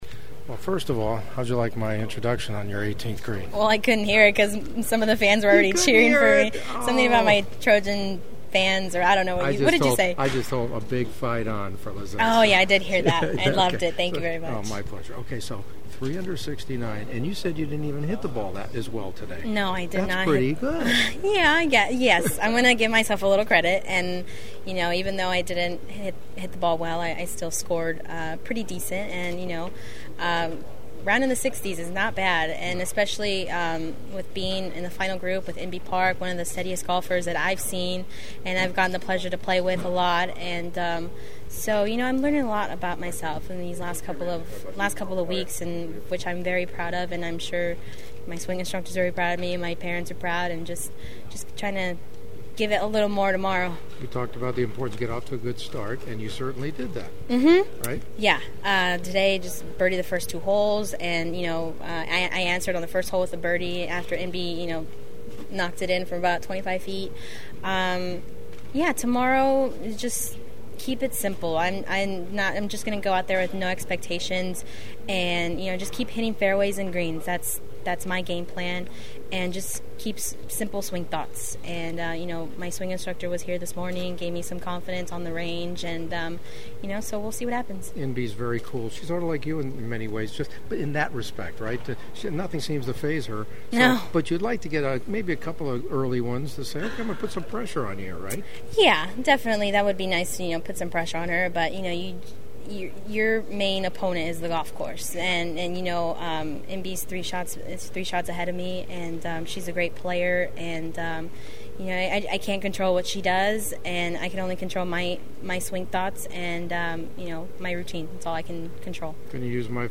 Lizette is just as cool chatting it up with me as she is on the course